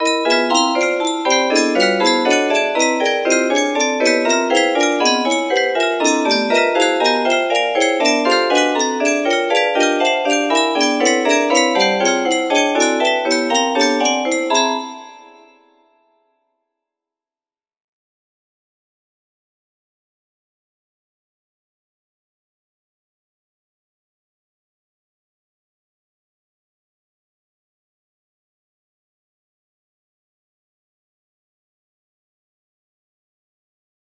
P19 music box melody